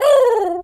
pigeon_call_angry_01.wav